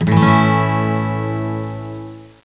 virtual guitar